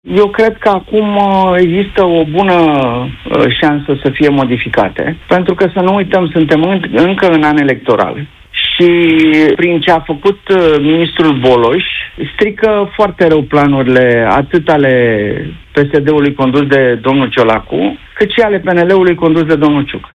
Gabriel Biriș, avocat specializat în fiscalitate, a explicat în emisiunea Deșteptarea” de la Europa FM că noile reguli în loc să ușureze activitatea contabililor, mai mult o încurcă, iar măsura nu contribuie la reducerea evaziunii fiscale.